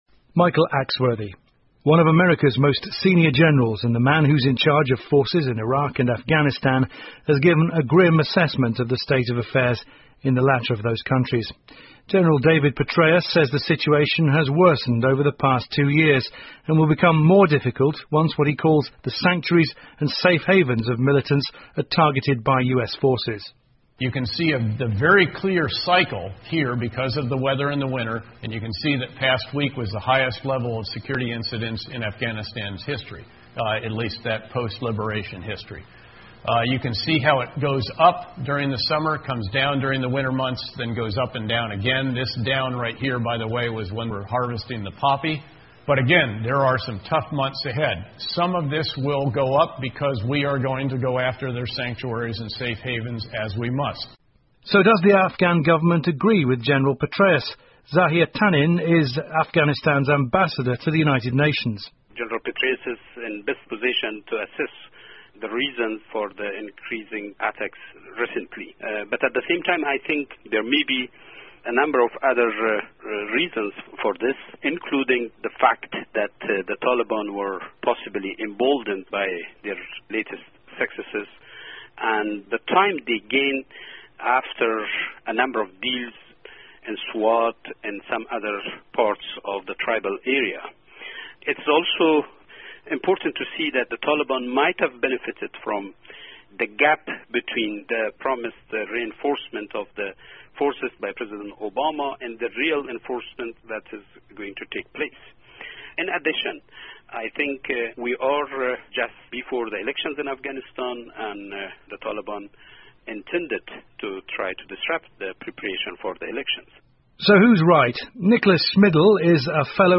英国新闻听力 阿富汗形势访谈 听力文件下载—在线英语听力室